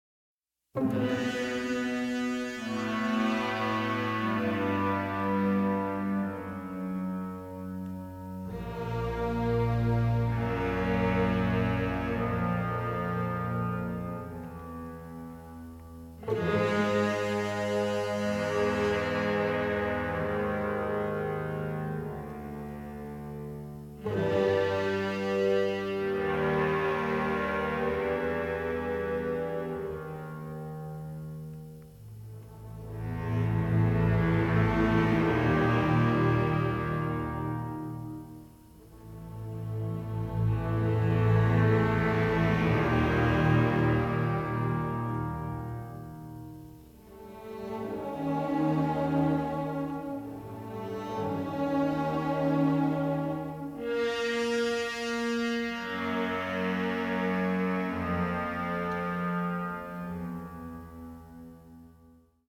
Analog Multi-Track Stereo Remix